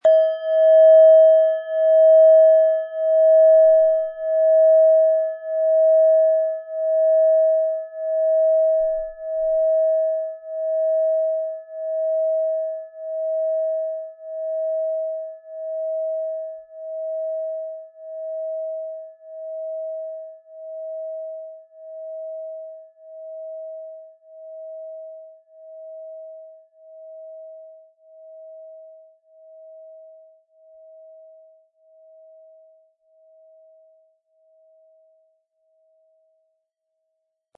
Japanische Klangschale Solfeggio 639 Hz - Verbindung
Die japanische Klangschale mit 639 Hertz entfaltet einen sanften Klang, der das Herz berührt und Verbundenheit stärkt.
Sorgfältig gearbeitete japanische Klangschalen mit 639 Hz zeichnen sich durch Reinheit und Klarheit im Ton aus. Der Nachhall wirkt weich und zugleich tragend.
MaterialBronze